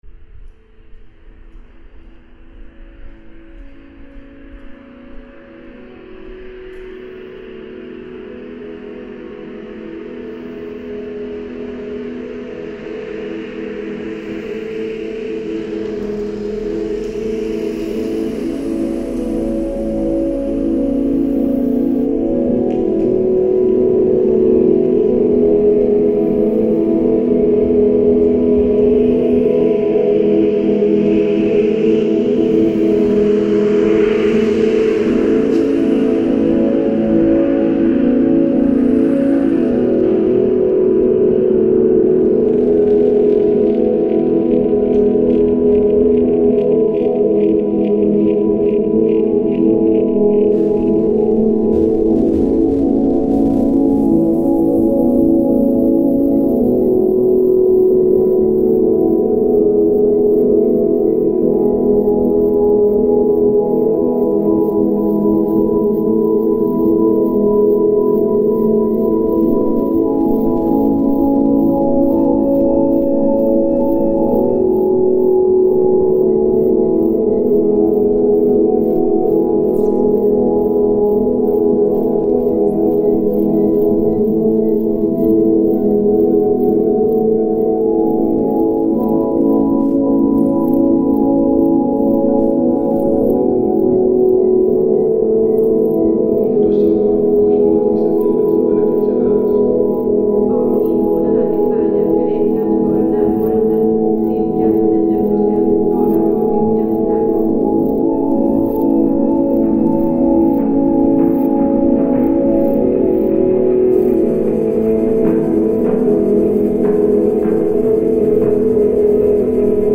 File under: Post-Everything / Electroacoustic / Glitch